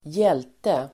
Uttal: [²j'el:te]